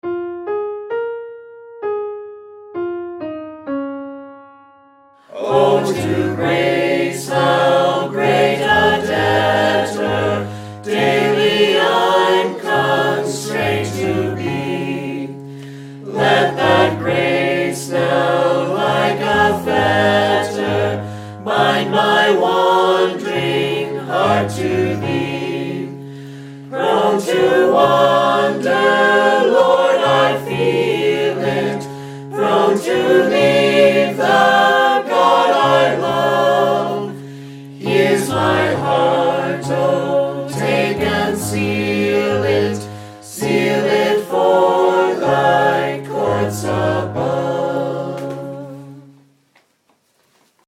The files below use a recording made at our retreat in July, when a number of us learned the harmonies for this hymn.
The harmony part is always illustrated in the left channel by a piano.  Each file begins with a piano lead in that gives the final phrase of the melody so you can practice making the transition to whatever harmony part you’ve chosen.
Come-Thou-Fount-Bass.mp3